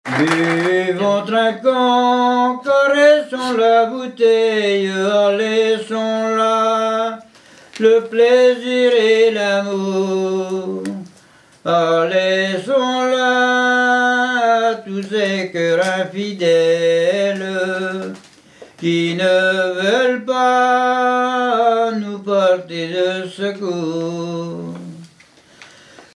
Genre strophique
10 ans de fêtes du chant à Bovel